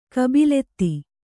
♪ kabiletti